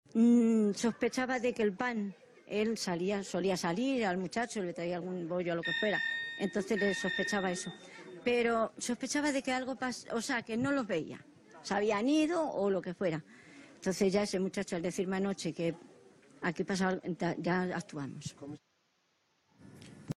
Escolteu què diu aquest testimoni: